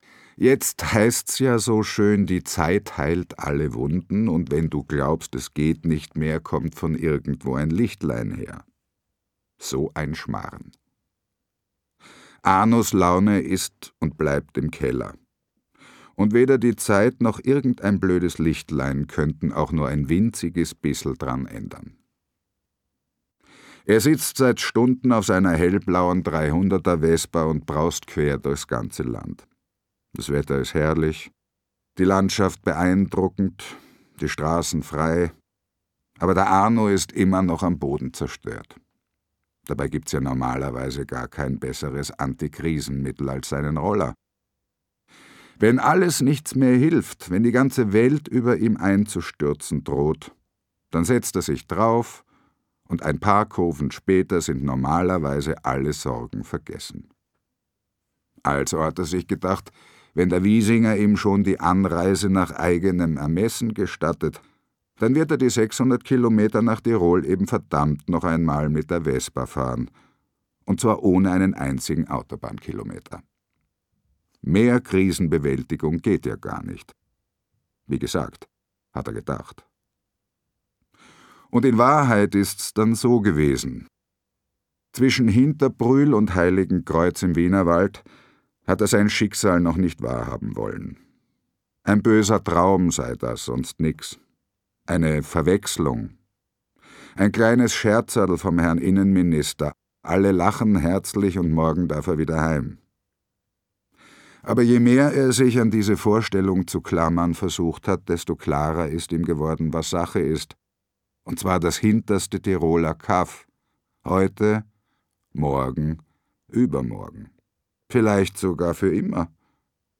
Der Tote im Schnitzelparadies Ein Fall für Arno Bussi Joe Fischler (Autor) Heikko Deutschmann (Sprecher) Audio Disc 2020 | 1.